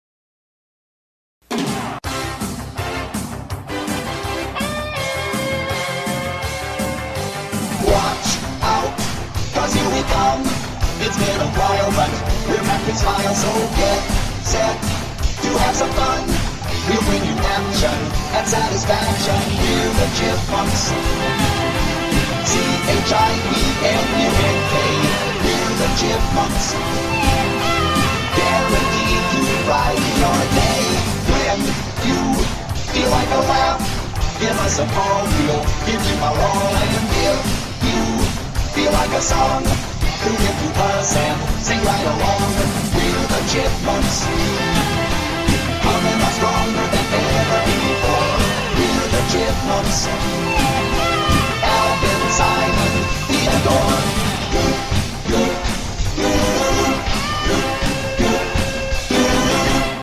Remastered with real voices (not sped up)